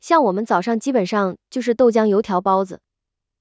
在副语言建模方面，团队推出的合成技术实现了声学模型对自然表达中出现的吸气、笑声、犹豫、修正等多种副语言现象建模，并且结合文本的语义信息自动插入副语言现象。